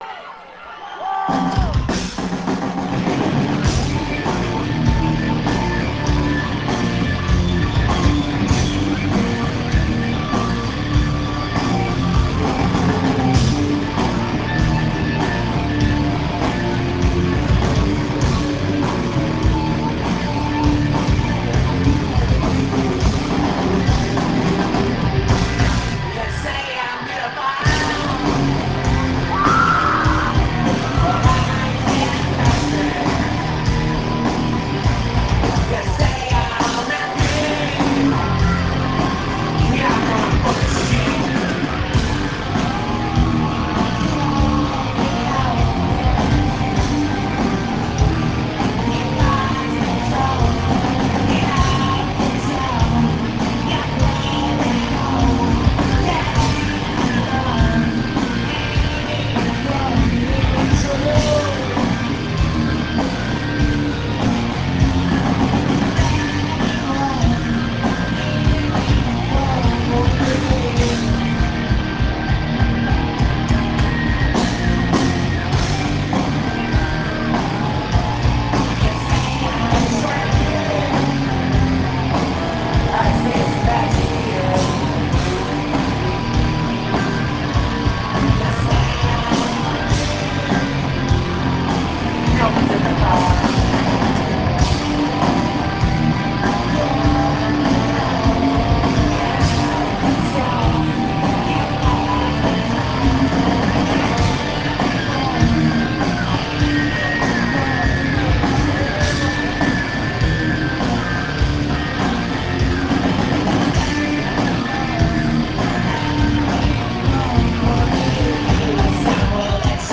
St. Andrew's Hall; Detroit, USA